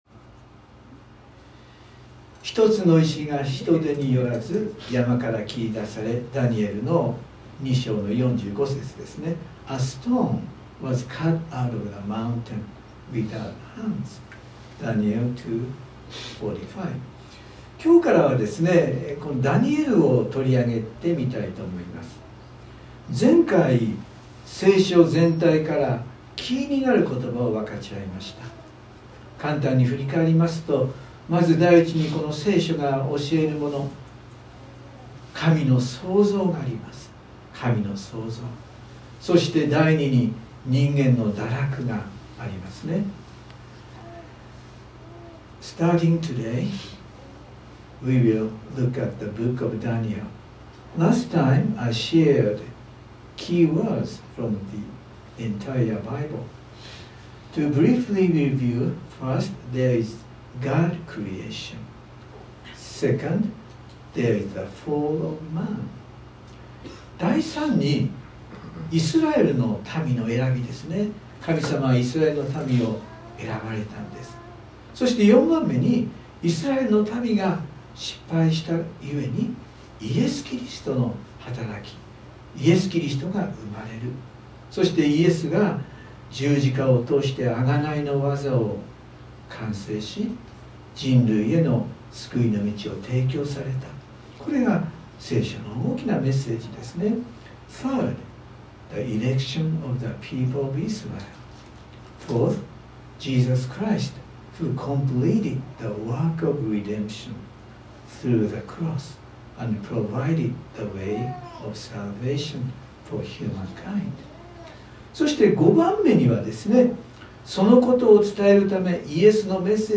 ↓Audio link to the sermon:(Sunday worship recording) (If you can’t listen on your iPhone, please update your iOS) Sorry, this post is no translate, only available in Japanese.